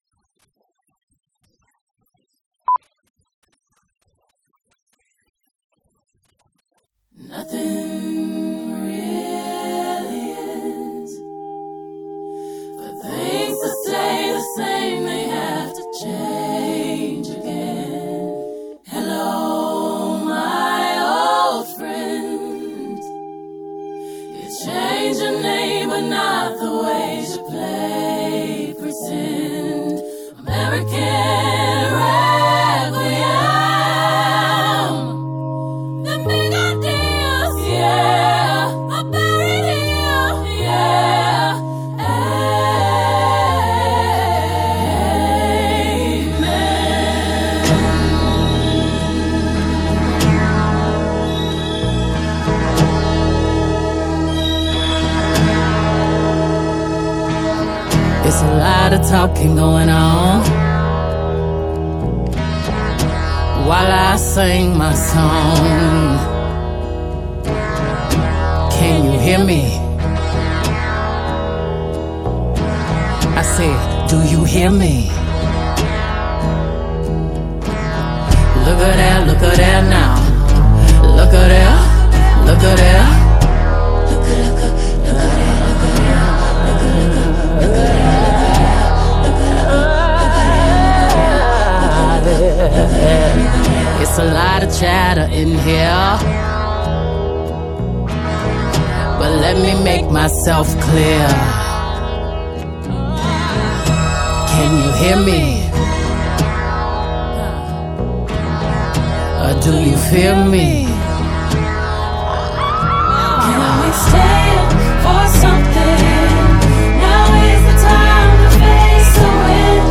آلبوم کانتری/پاپ
Country, Pop, R&B